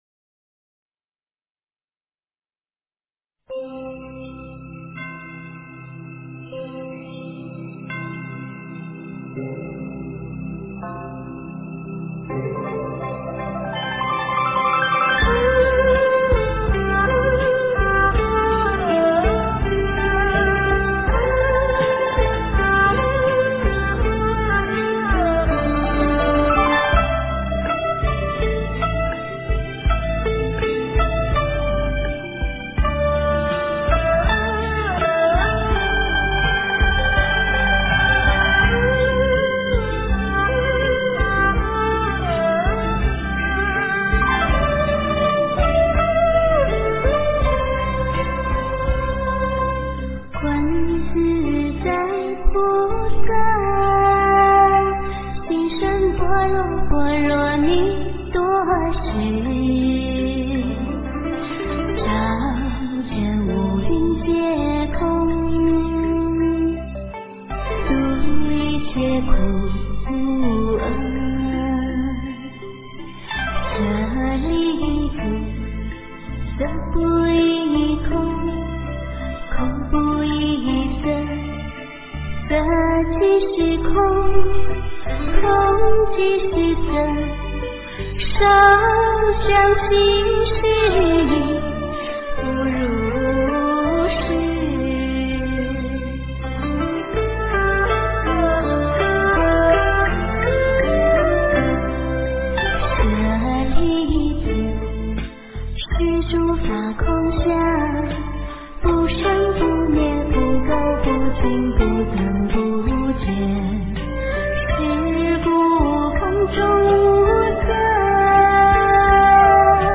诵经
佛音 诵经 佛教音乐 返回列表 上一篇： 般若波罗蜜多心经-藏文版 下一篇： 大悲咒 相关文章 Way To Kundalini-灵性音乐--The Buddhist Monks Way To Kundalini-灵性音乐--The Buddhist Monks...